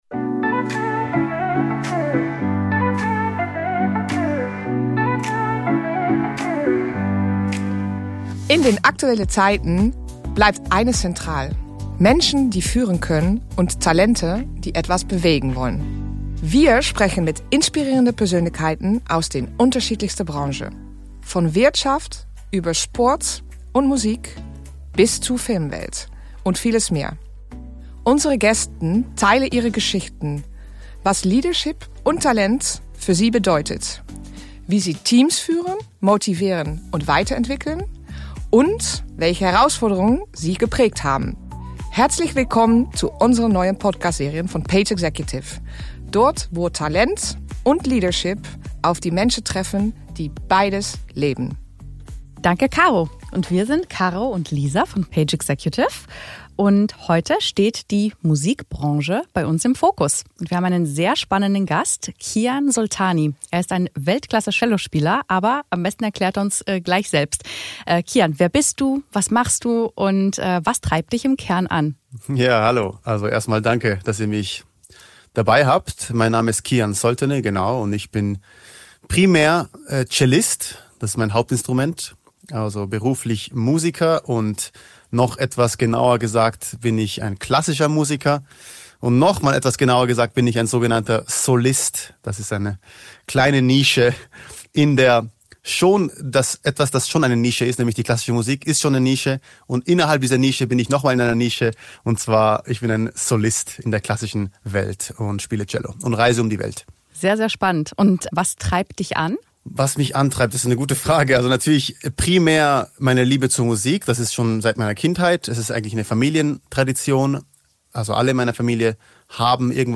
In dieser Folge sprechen wir mit Kian Soltani, einer Ausnahmefigur der klassischen Musik.